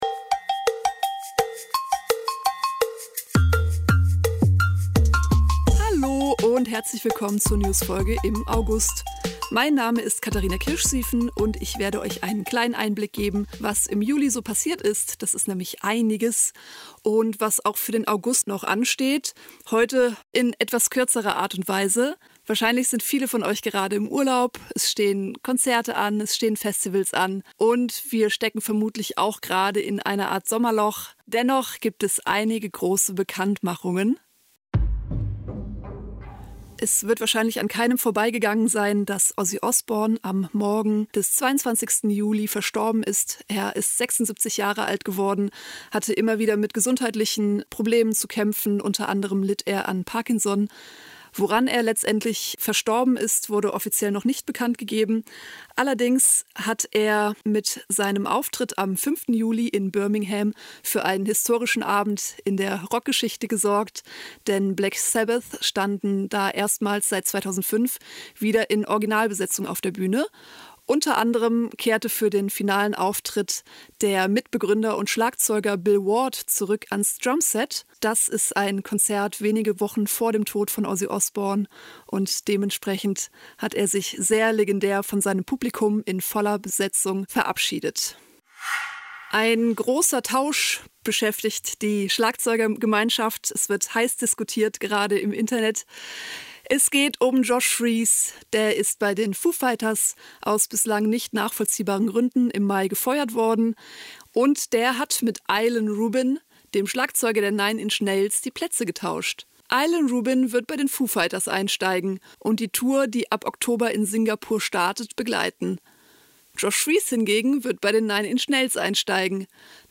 Podcast am Morgen beim Aufstehen anmachen und versuchen, aufzustehen, bevor zum Ende der Episode die Agogo Bells erklingen.